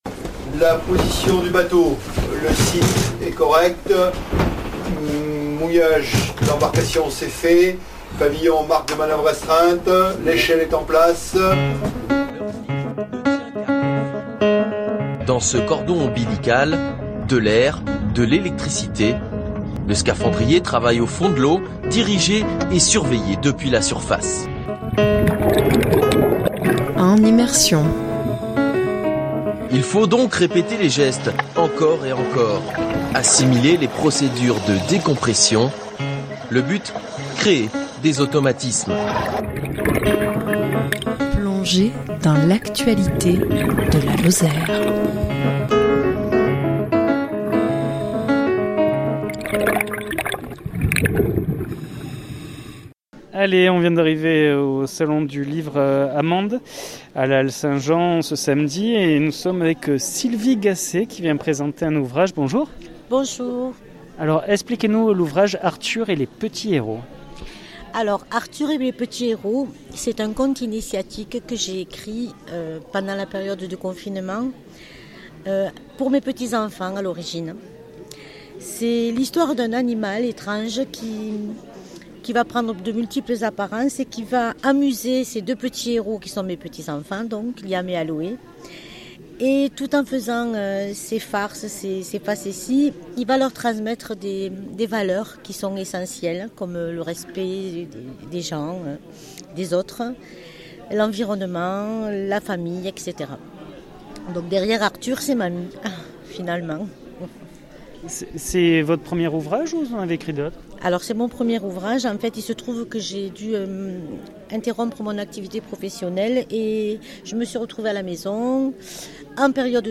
En immersion salon du livre.mp3